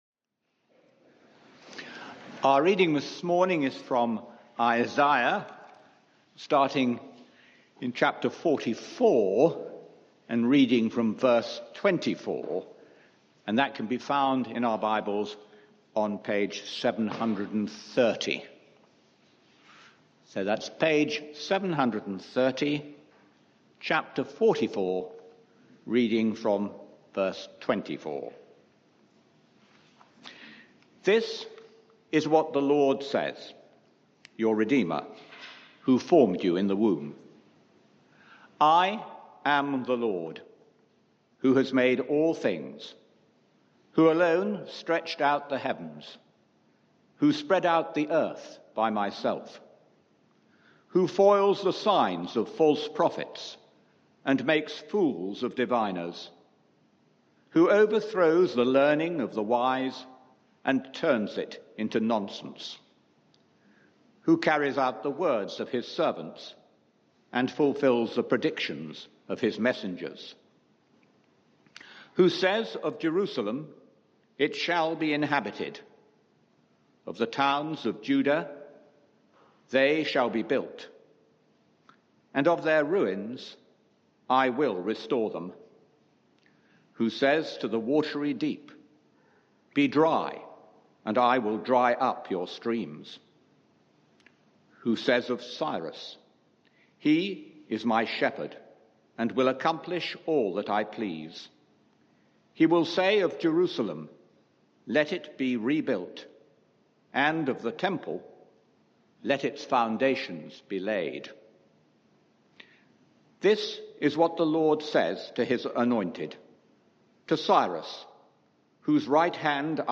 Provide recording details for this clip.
Media for 11am Service on Sun 06th Mar 2022 11:00 Speaker